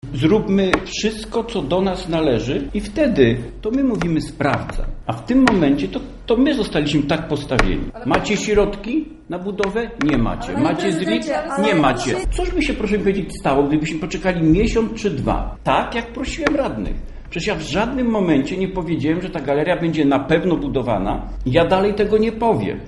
- Musimy zrobić wszystko, by firma nie mogła nam nic zarzucić - mówi wiceprezydent Rybak.